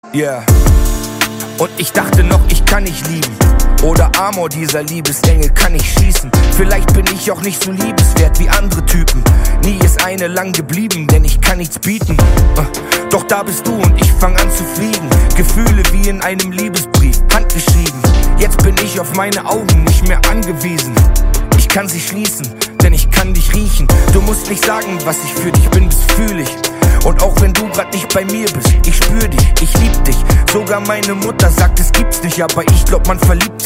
Kategorie Rap/Hip Hop